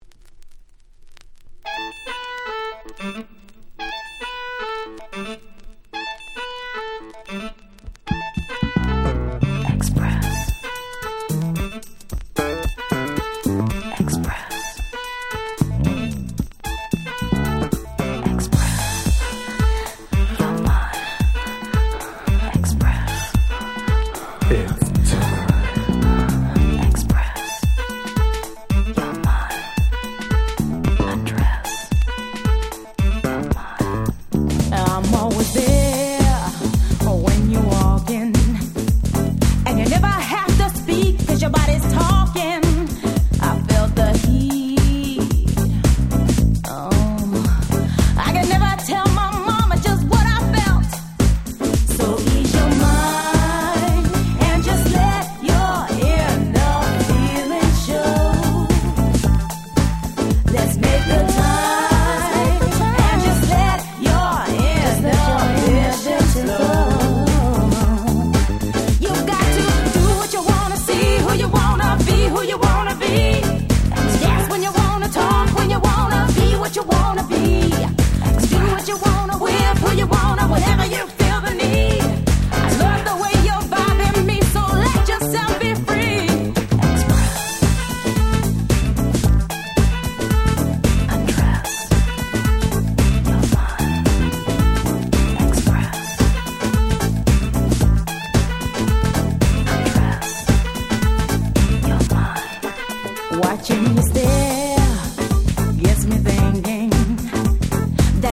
93' Nice R&B Album !!
90's キャッチー系 ボーカルハウス